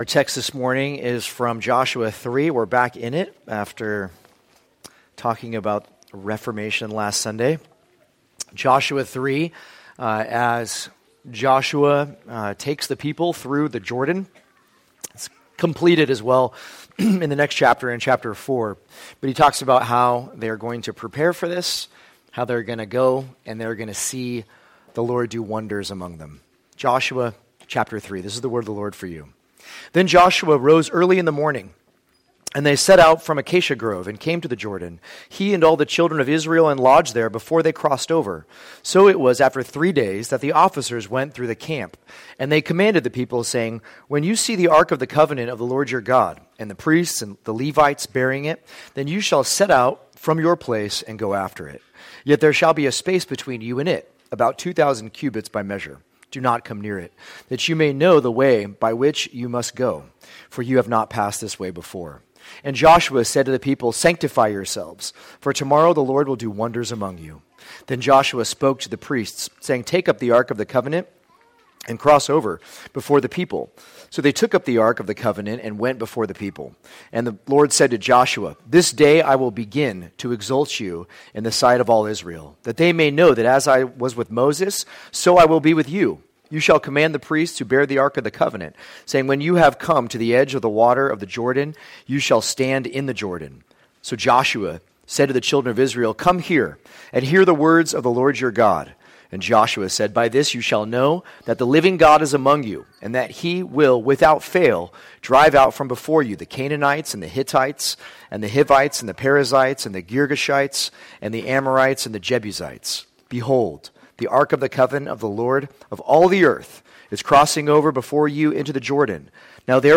2024 Wonders Among You Preacher